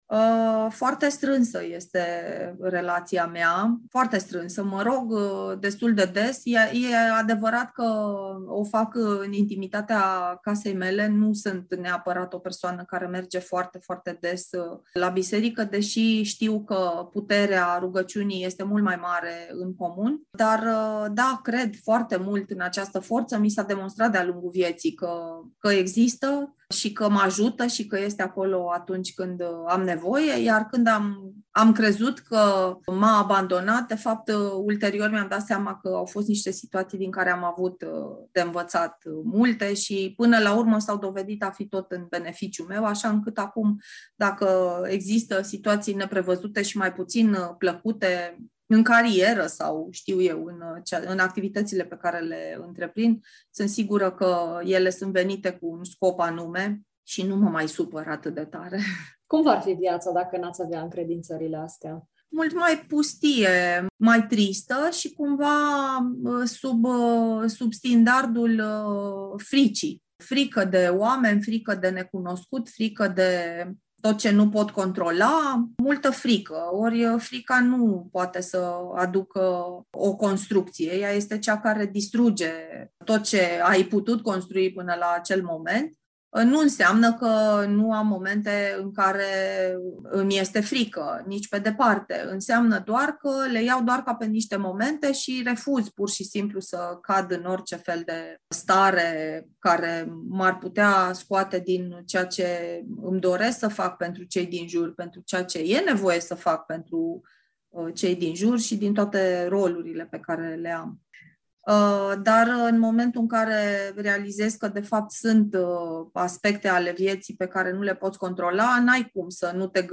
prof. universitar